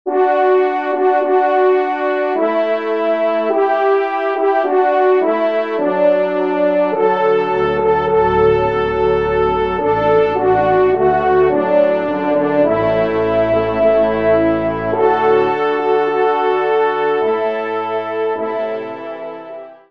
Opus 142 : 20 sonneries pour Cors et Trompes de chasse